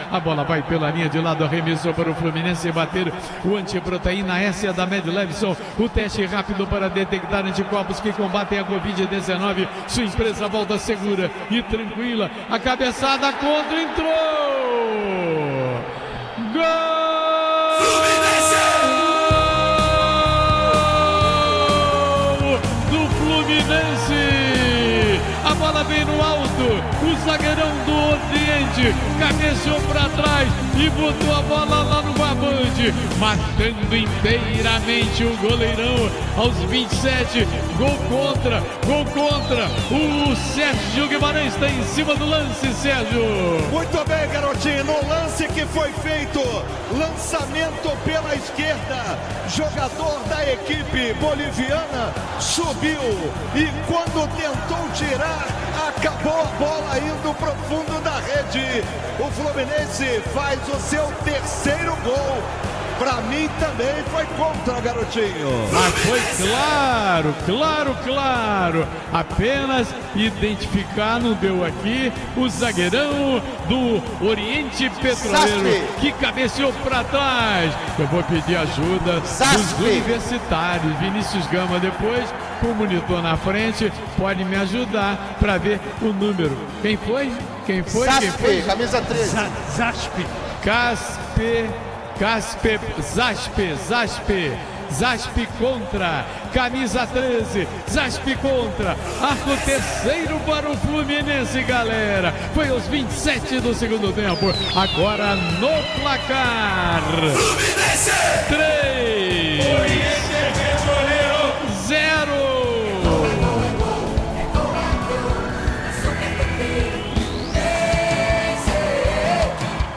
Ouça os gols da vitória do Fluminense sobre o Oriente Petrolero com a narração do Garotinho